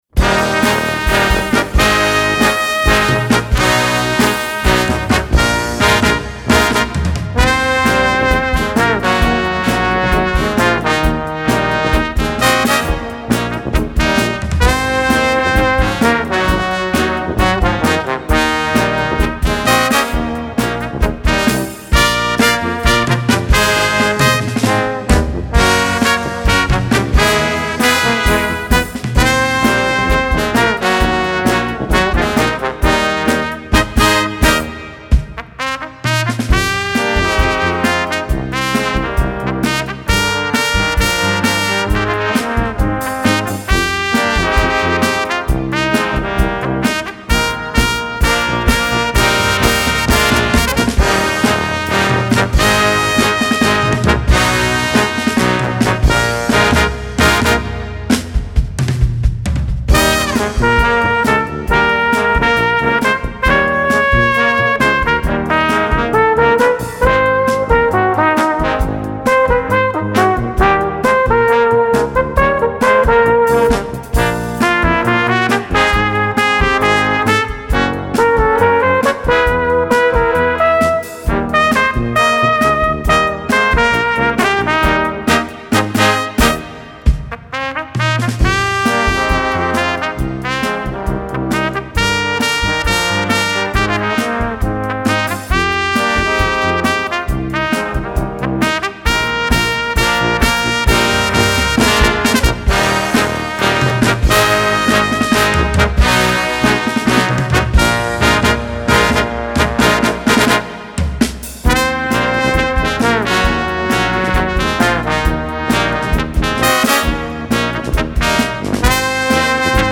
Gattung: Kleine Besetzung
Besetzung: Kleine Blasmusik-Besetzung
Stimmensatz Sextett: